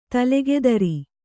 ♪ talegedari